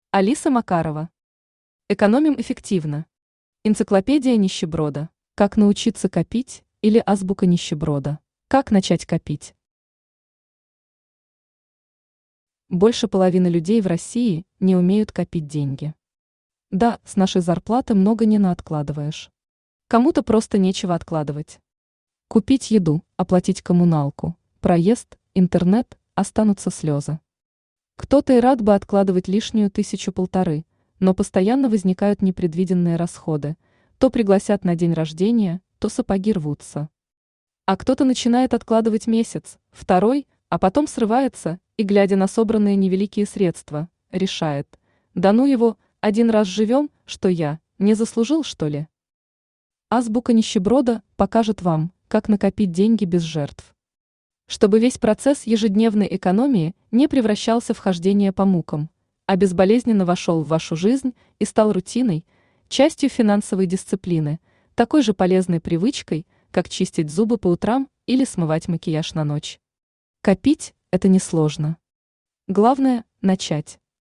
Энциклопедия нищеброда Автор Алиса Макарова Читает аудиокнигу Авточтец ЛитРес.